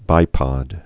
(bīpŏd)